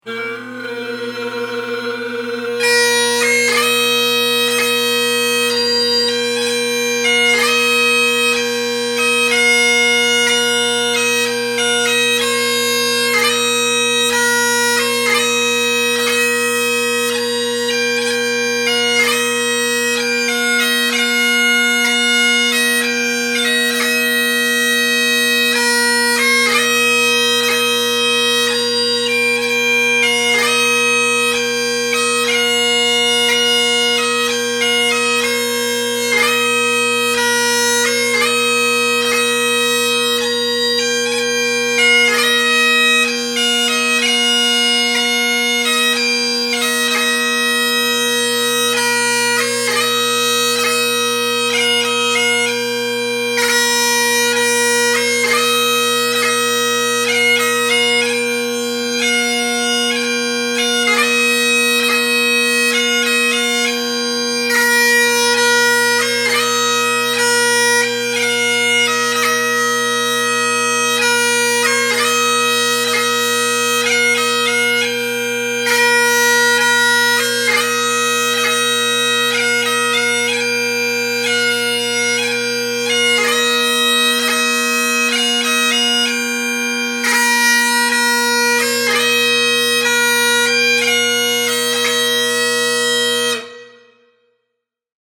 Halifax Bagpiper